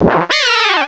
Cri de Chaffreux dans Pokémon Diamant et Perle.